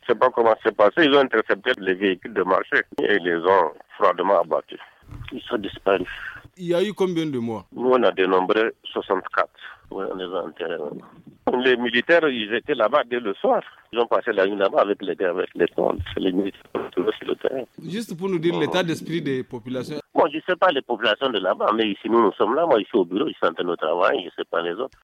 Interview Illiassou Soumana, maire de la commune de Banibongou